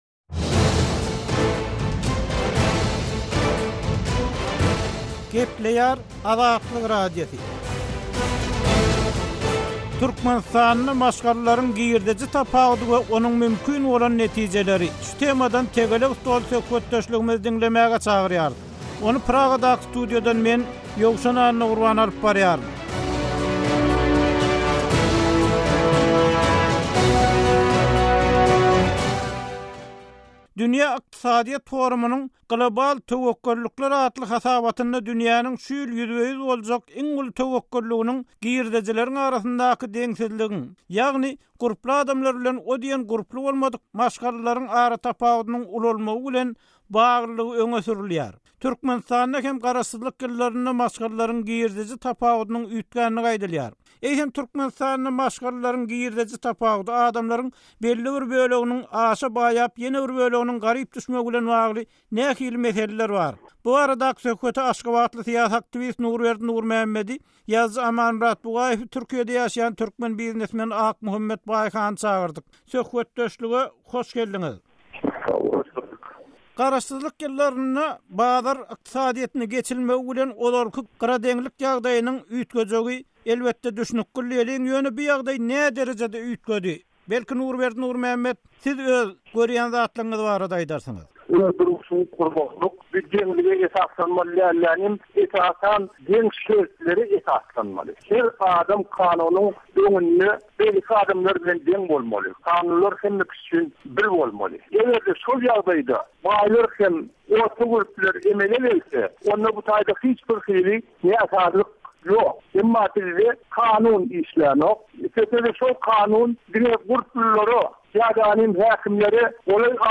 Tegelek stol: "Baýlar" we "garyplar" ýurt üçin töwekgellikmi?